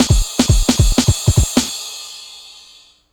drums06.wav